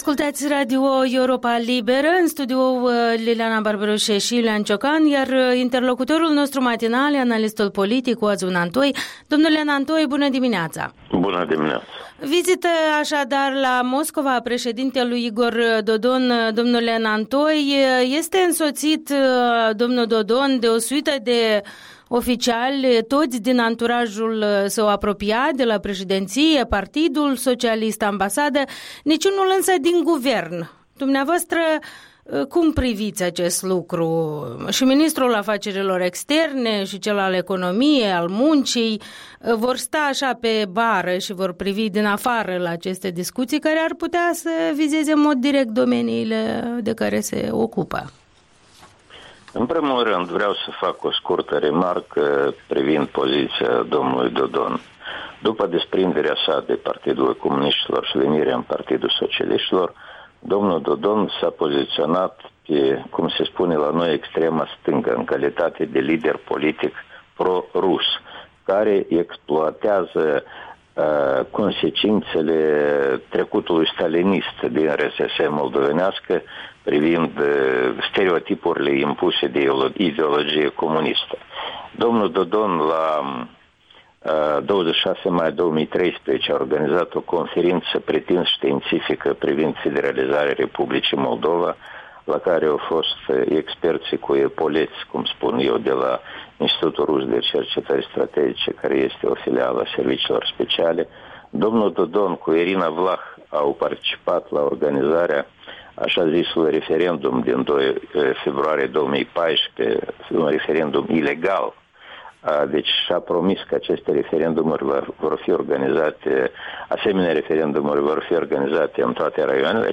Interviul dimineții despre vizita președintelui Dodon la Moscova cu un comentator politic avizat.
Interviul dimineții: cu analistul politic Oazu Nantoi